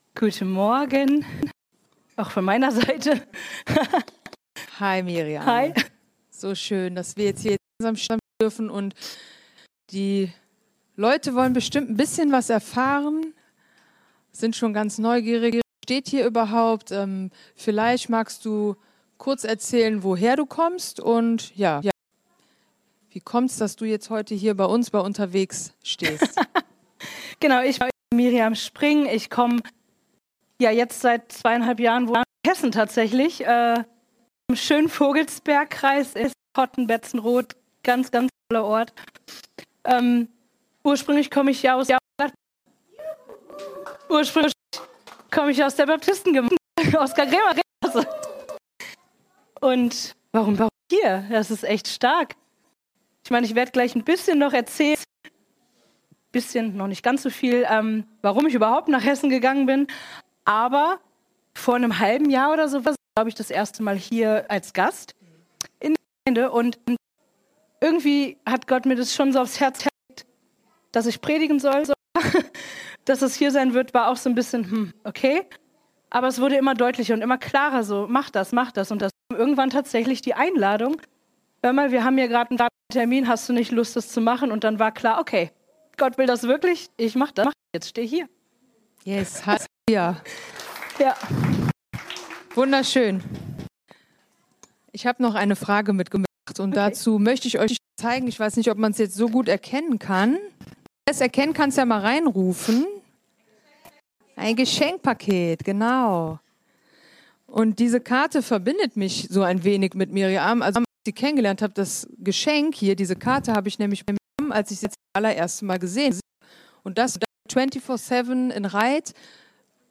Dieses Video wurde mit anderer Tonspur (Predigt) neu erstellt.